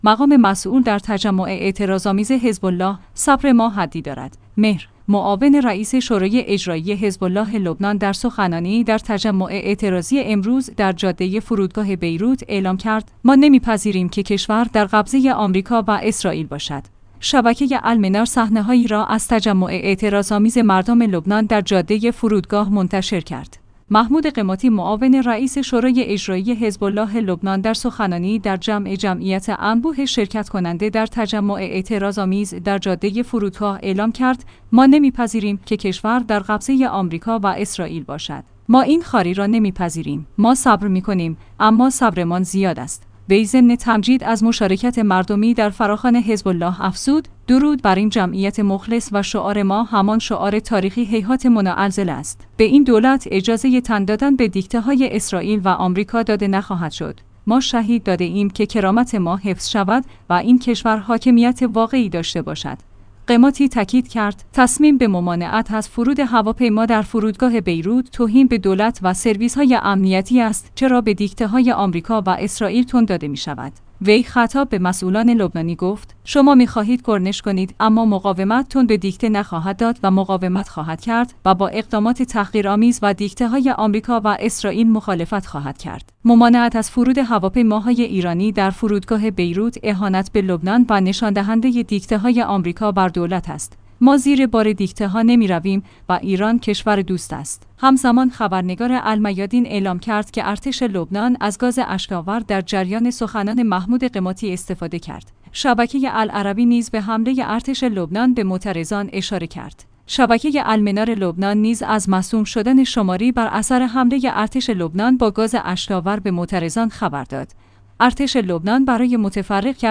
مقام مسئول در تجمع اعتراض‌آمیز حزب‌الله : صبر ما حدی دارد